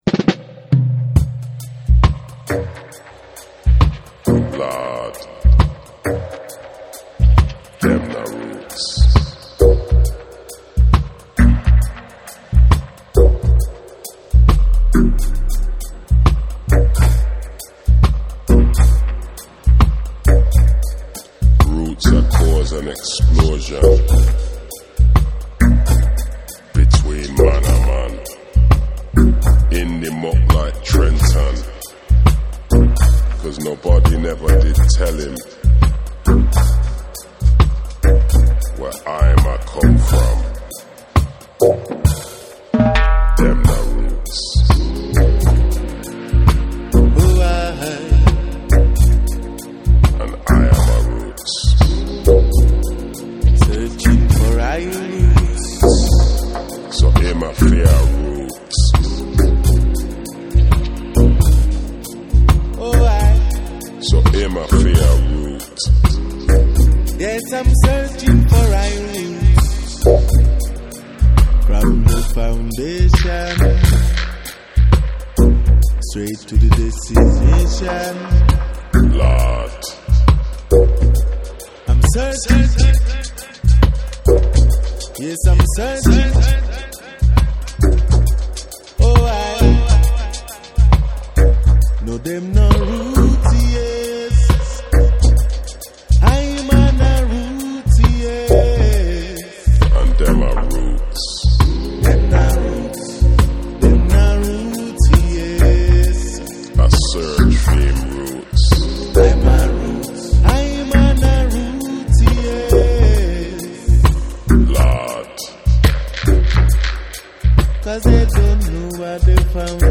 両面ともに彼ららしいモノクロームな質感の、ディープな空間が広がるダブ・サウンドを聴かせている。